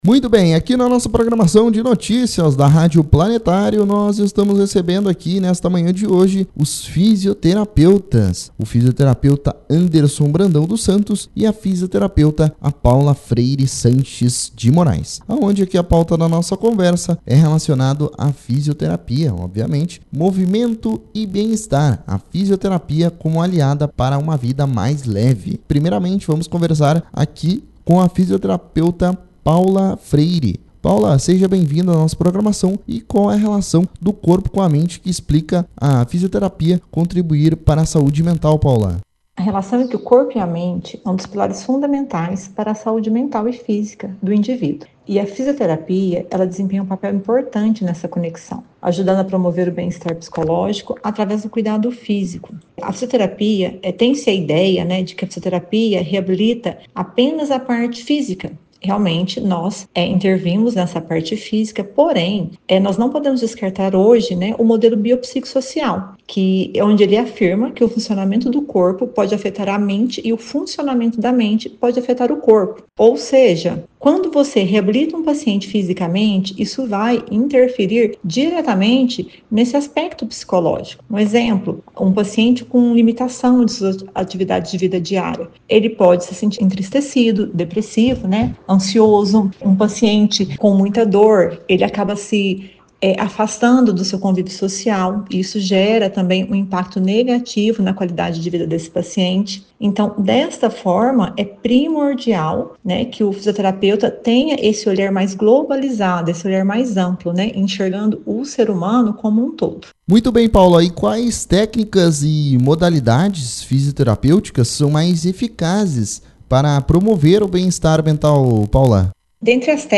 Confira reportagem completa a seguir.